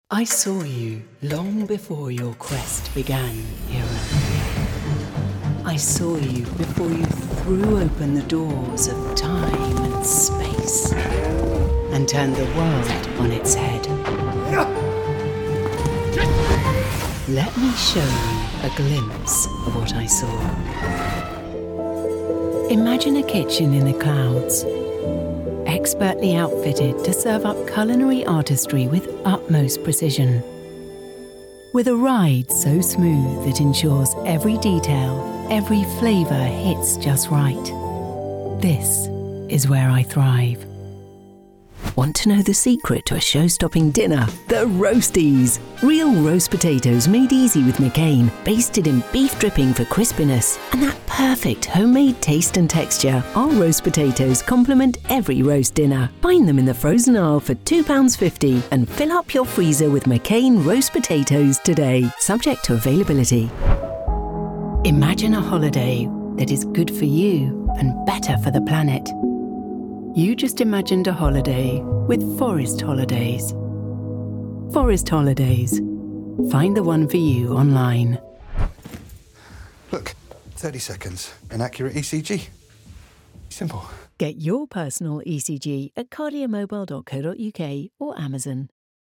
Anglais (Britannique)
Commerciale, Chaude, Polyvalente, Amicale, Corporative